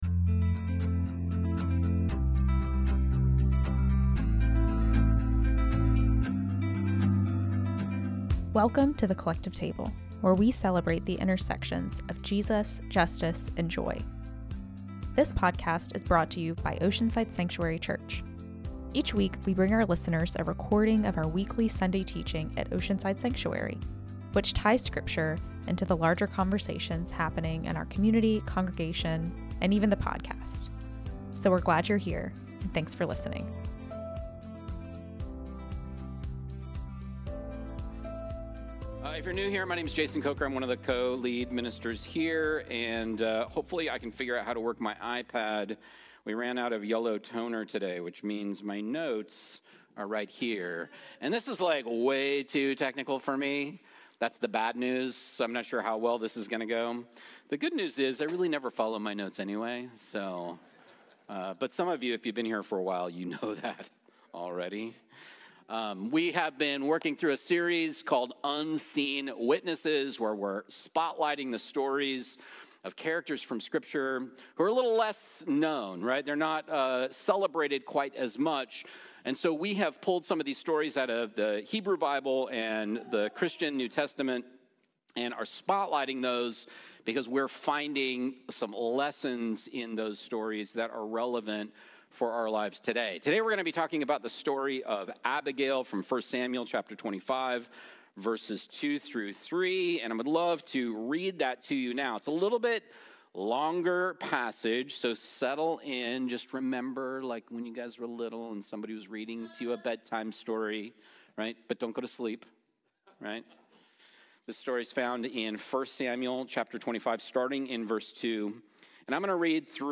Unseen Witnesses Ep.7 - “Evil Has Been Decided" - OSC Sunday Sermons